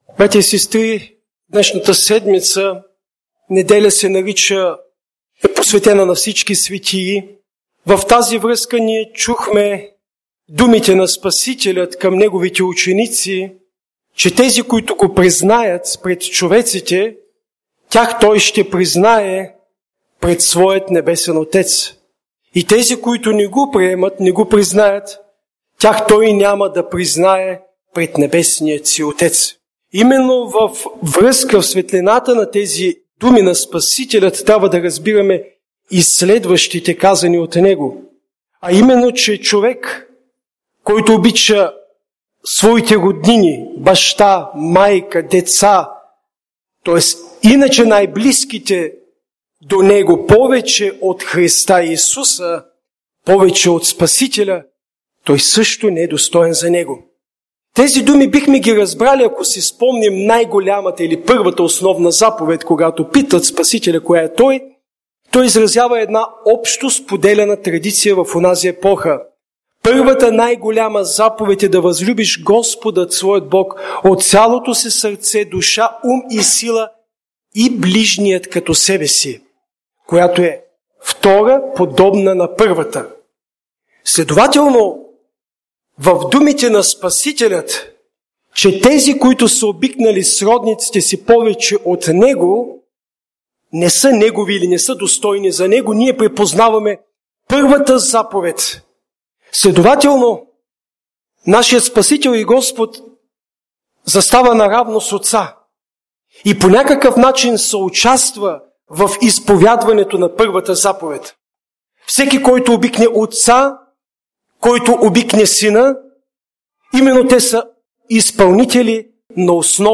1 Неделя след Петдесетница – на Всички светии – Проповед
Неделни проповеди